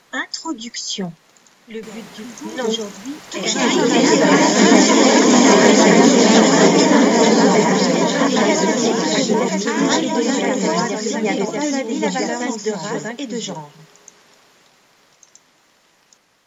Voix off française féminine institutionnelle, calme pour des spots publicitaires ou narration.
Sprechprobe: Industrie (Muttersprache):
French voice over artist with a naturally warm, articulate and engaging voice, specializing in audio books, childrens narration.
I work from my home studio and can normally deliver within 24 hours.